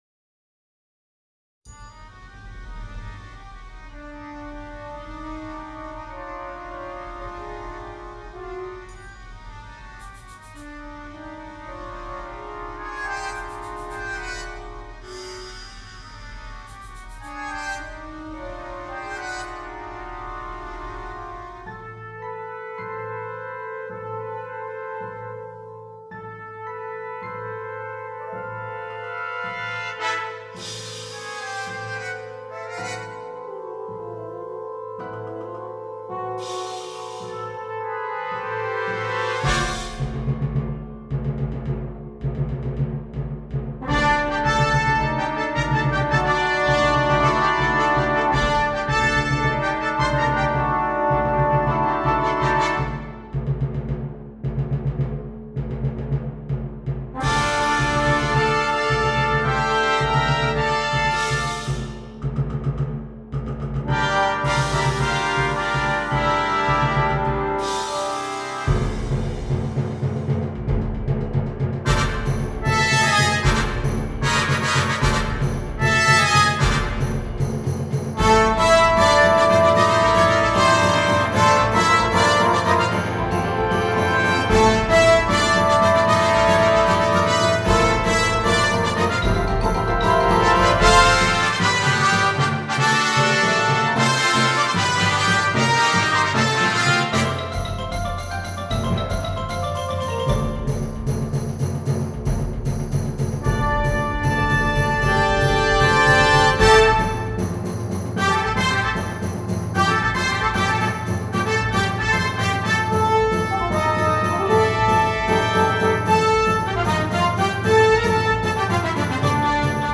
20 Trumpets and Percussion